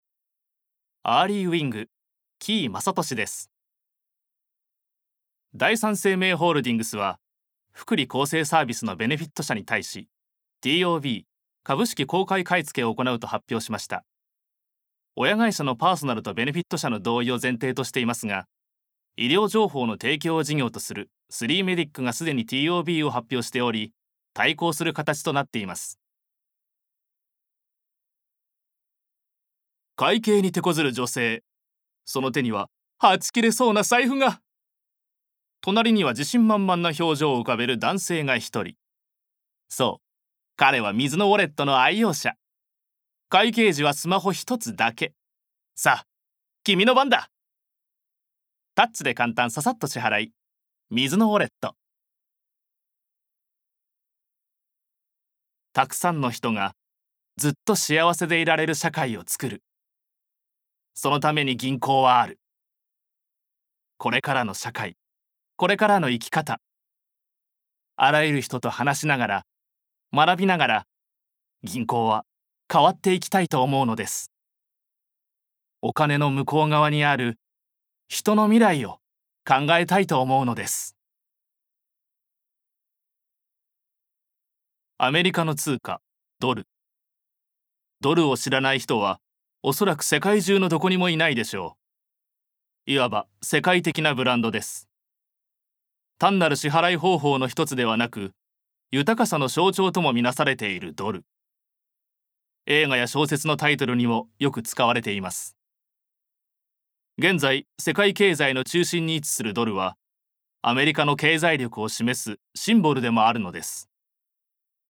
ボイスサンプル
ナレーションALL